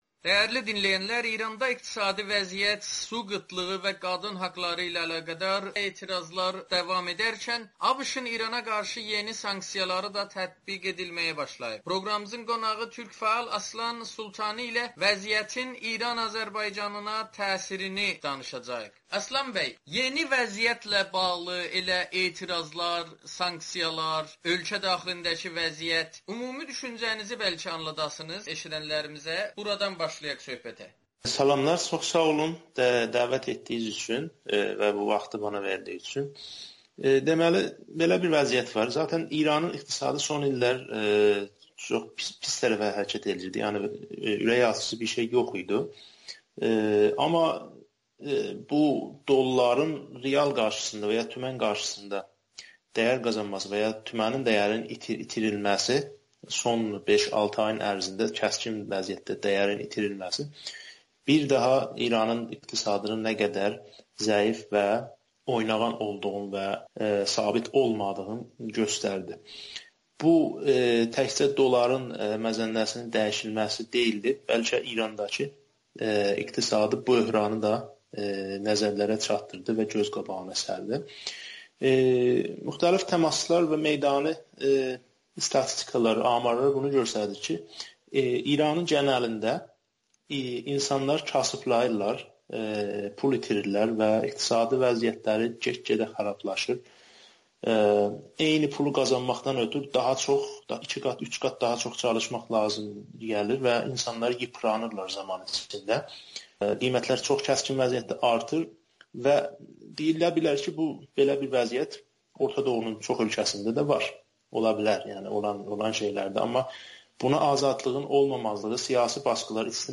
Təbriz və Tehran arasında dərin bir etimadsızlıq var [Audio-Müsahibə]